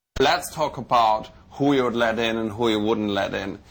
Bruno Movie Sound Bites